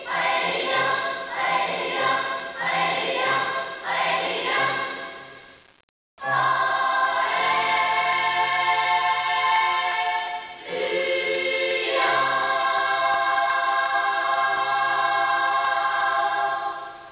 学級全員による合唱です。
聞かせどころだけを録音しました。
もとは体育館でステレオ録音したものです。
ハレルヤコーラス ３部合唱 内田伊佐治 ヘンデル あります